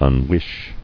[un·wish]